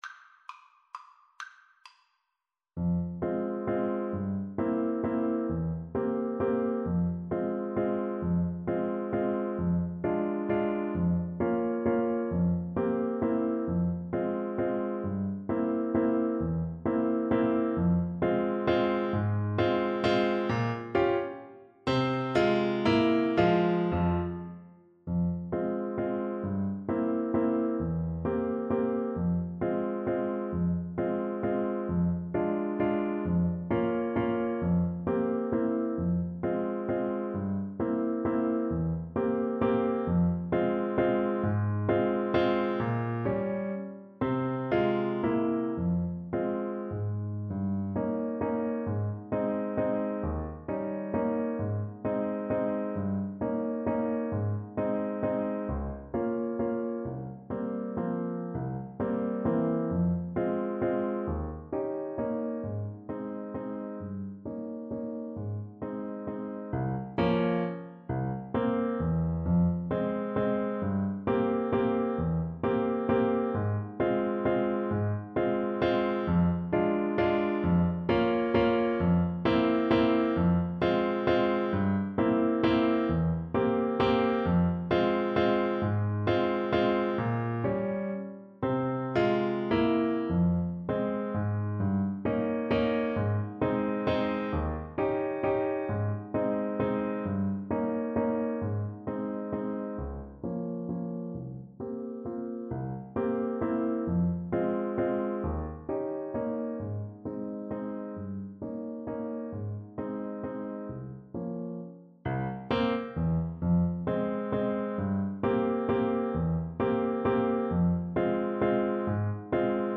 Moderato ( = 132)
3/4 (View more 3/4 Music)
Classical (View more Classical Tenor Saxophone Music)